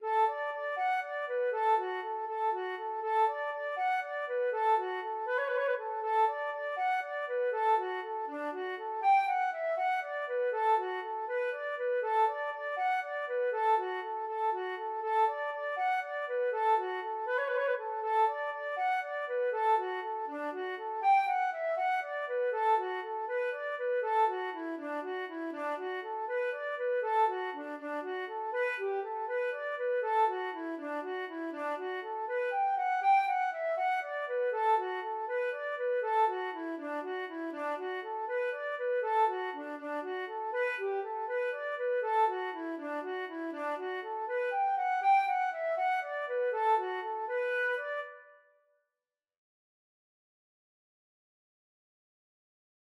D major (Sounding Pitch) (View more D major Music for Flute )
6/8 (View more 6/8 Music)
Flute  (View more Intermediate Flute Music)
Traditional (View more Traditional Flute Music)
Irish